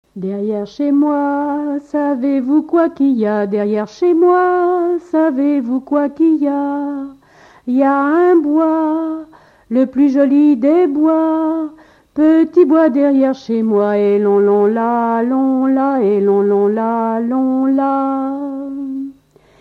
Genre énumérative
Catégorie Pièce musicale inédite